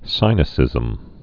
(sīnĭ-sĭzəm, sĭnĭ-)